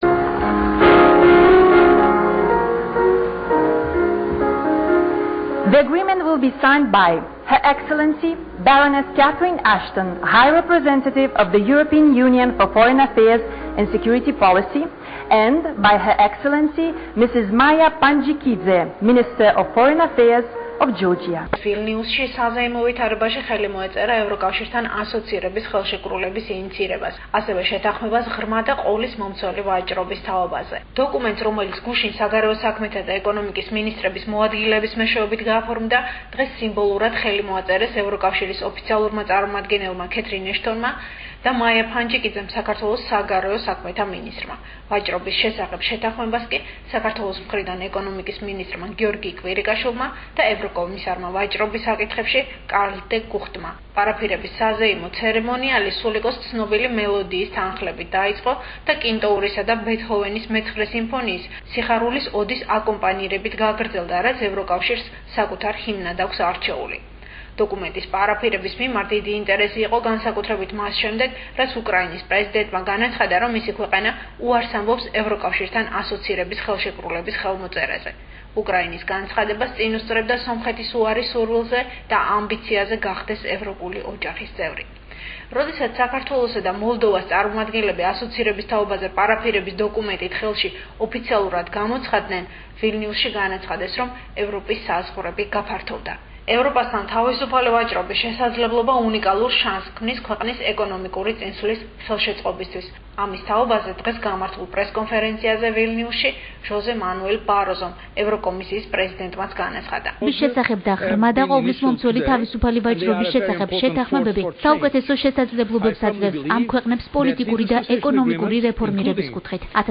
საზეიმო ცერემონიალი „სულიკოს“ თანხლებით